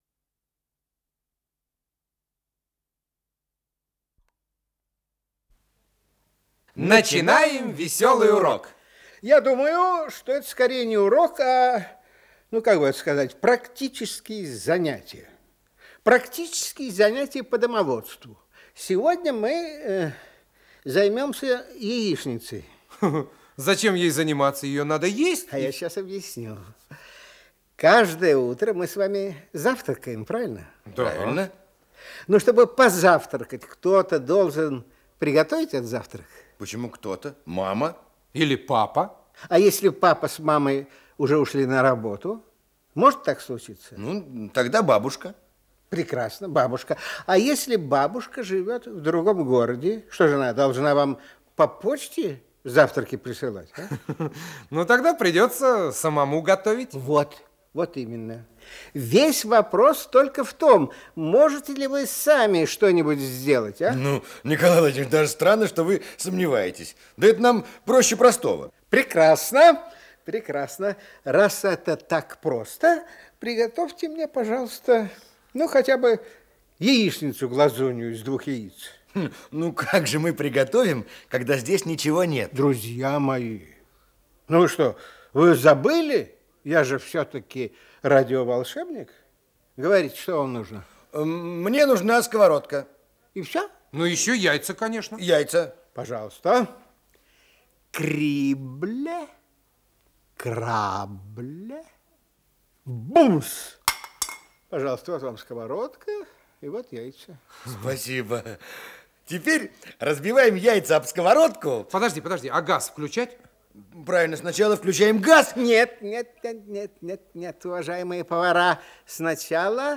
с профессиональной магнитной ленты
ПодзаголовокЯичница-глазунья, интермедия
ИсполнителиНиколай Литвинов
Скорость ленты38 см/с